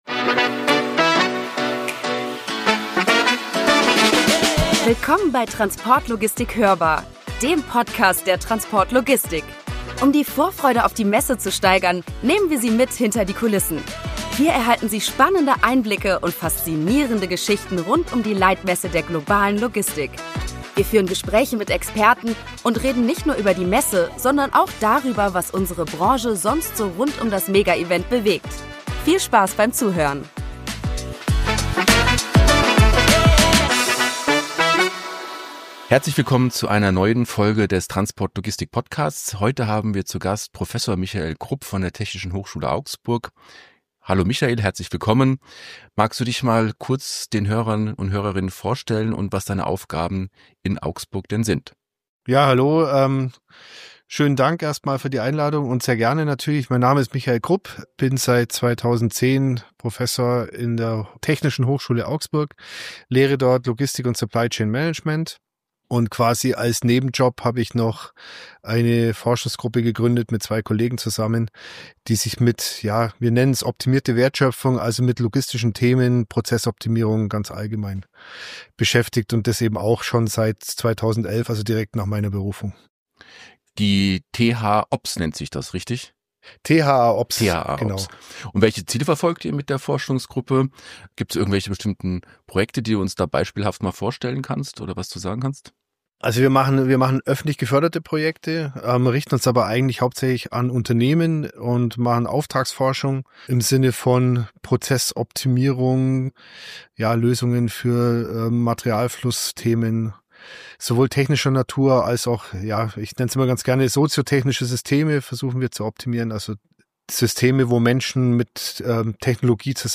Gemeinsam diskutieren wir die Herausforderungen und Chancen in der Logistikausbildung, insbesondere in Zeiten der Digitalisierung und des Fachkräftemangels. Dabei beleuchten wir auch die Bedeutung der Zusammenarbeit von Studierenden mit Unternehmen und die Integration neuer Technologien wie KI in die Logistik. Freuen Sie sich auf einen informativen Austausch über die Zukunft der Logistik und ihre Ausbildung.